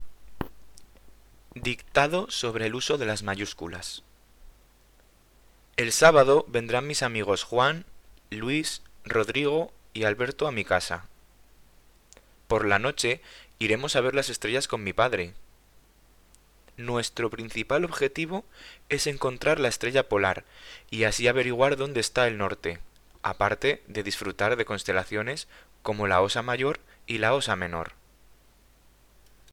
Dictado
m2_Dictado.mp3